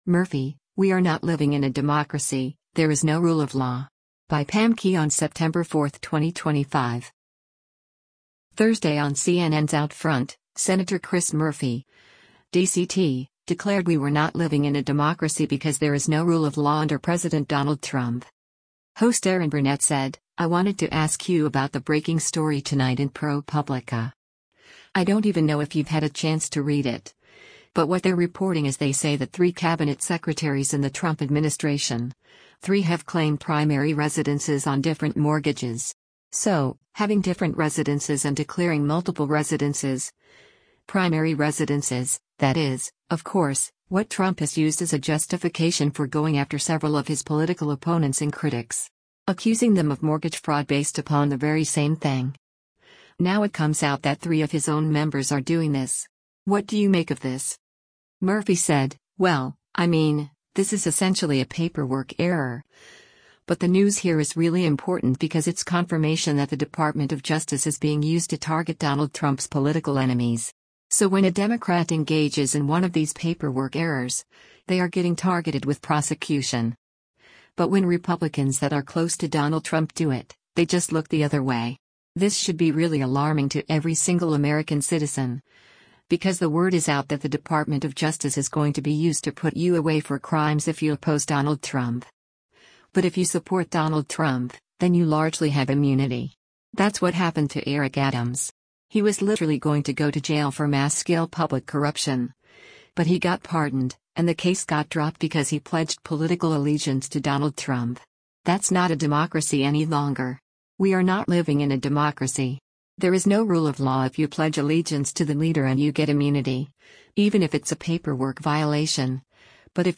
Thursday on CNN’s “OutFront,” Sen. Chris Murphy (D-CT) declared we were not “living in a democracy” because “there is no rule of law” under President Donald Trump.